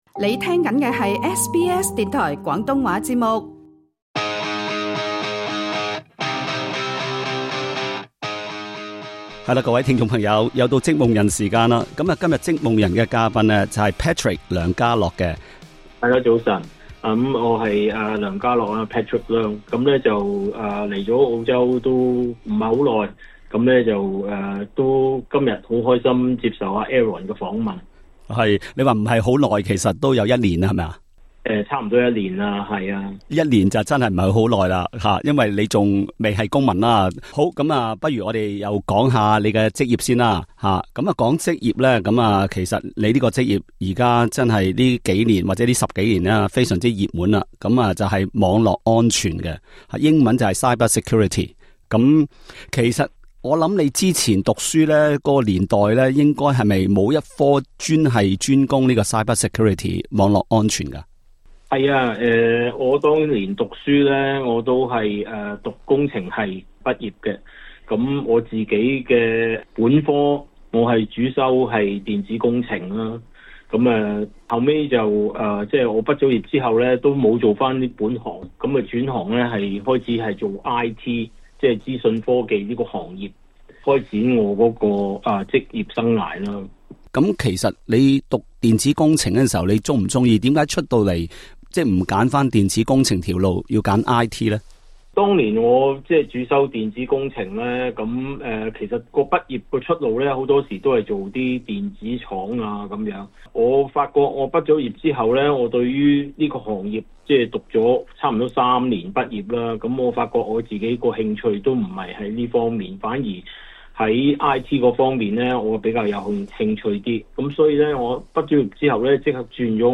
在本集《職夢人》談訪中